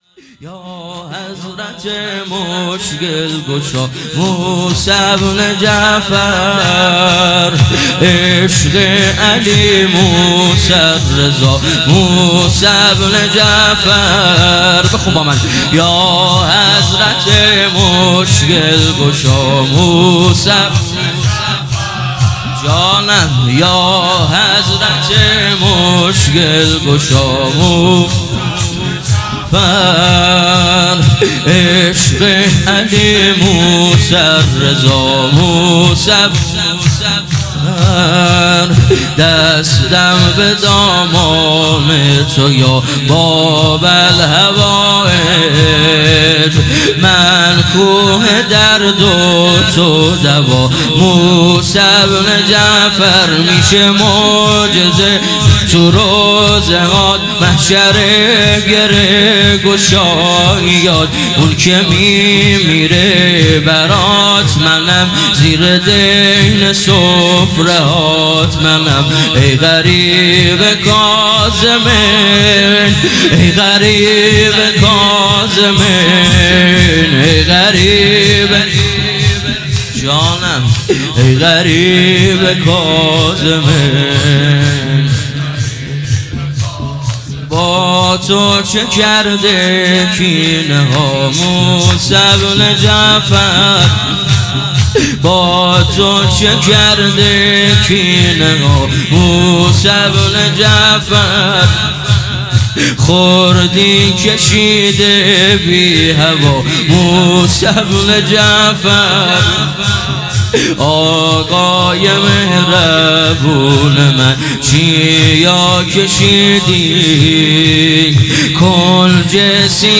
شور | یا حضرت مشکل گشا